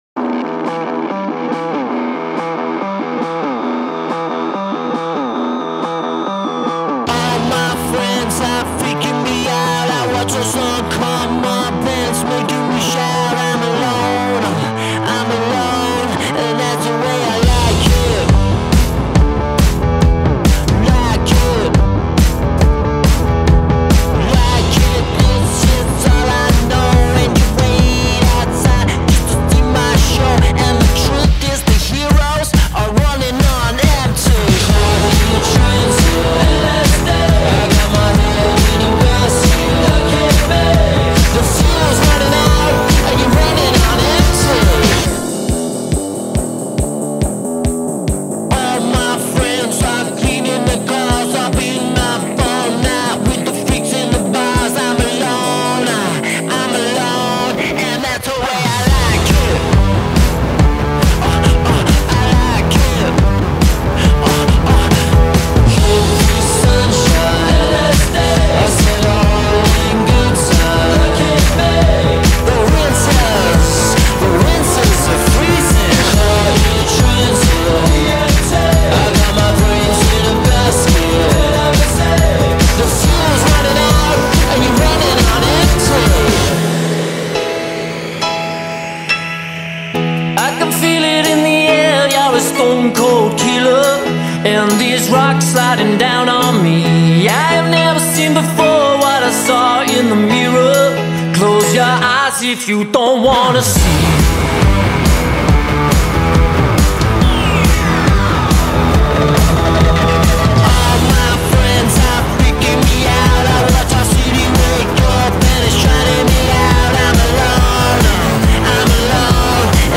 It feels loose but never lazy.